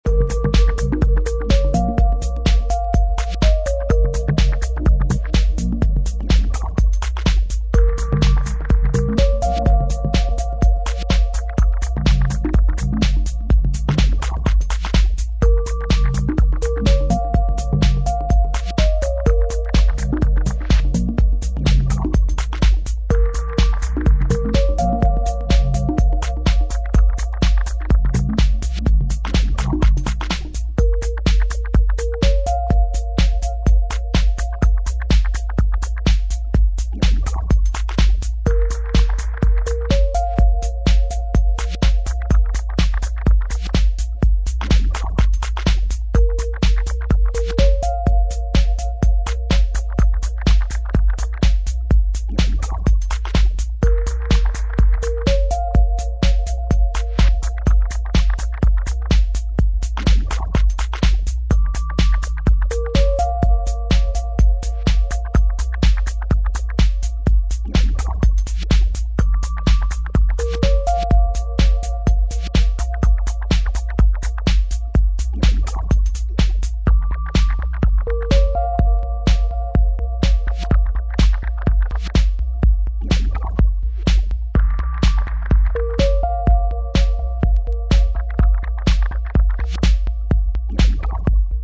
heavy duty tunes
Techno